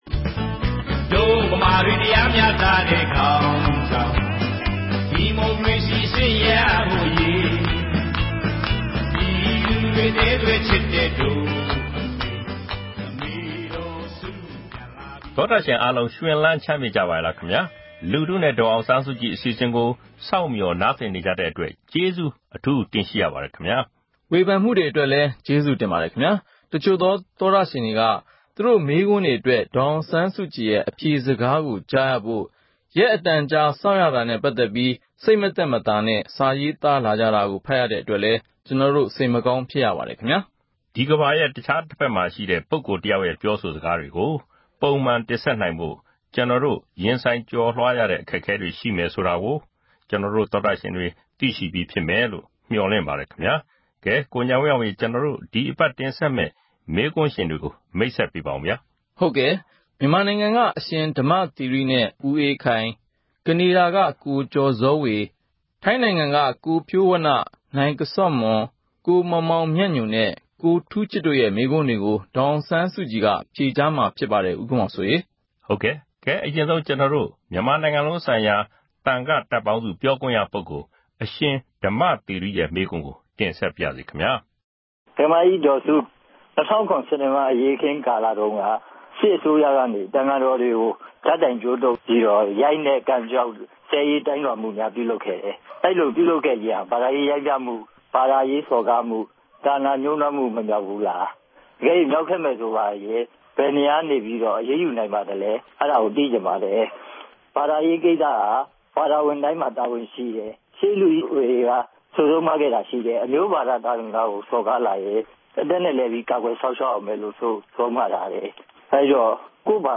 ‘လူထုနှင့် ဒေါ်အောင်ဆန်းစုကြည်’ အပတ်စဉ်အမေးအဖြေ